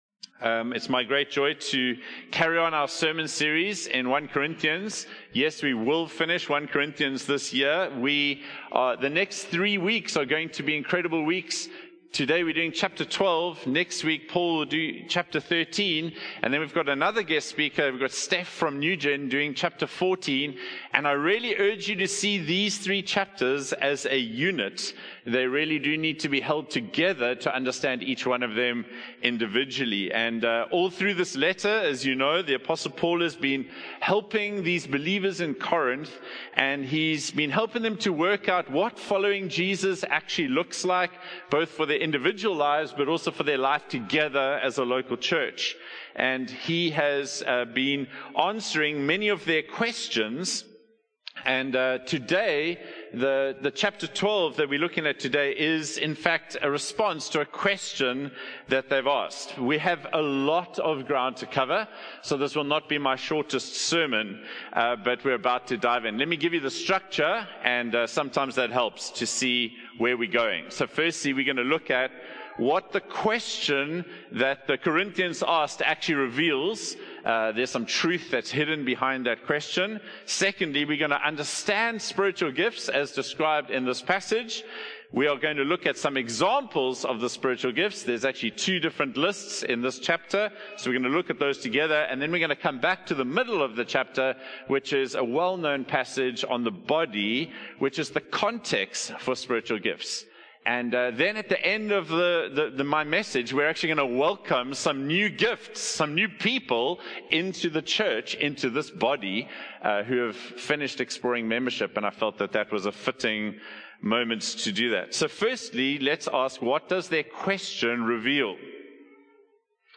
From Series: "Standalone Sermons"
One-Hope-Sermon-5-Oct-2025.mp3